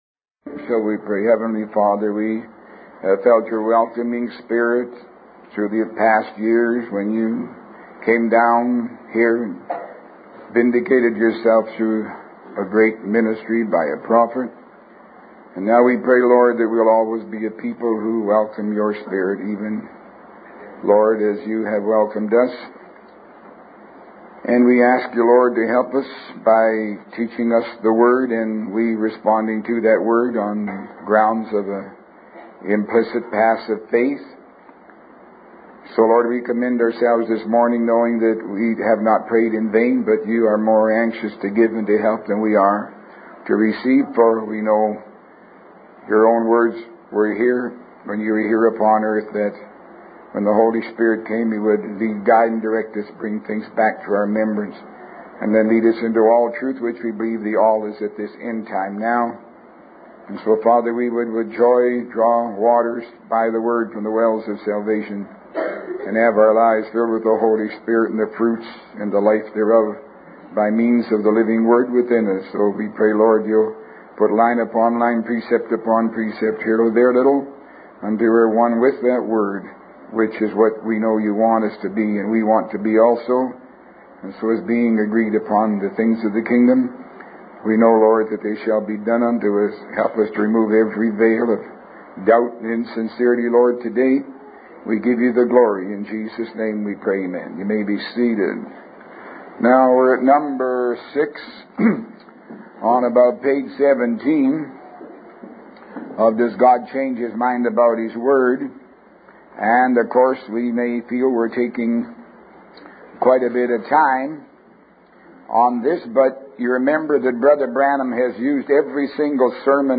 Year Month Day Tape No. Sermon Title Audio TX 1991 Oct 26 5191 Does God Ever Change His Mind About His Word?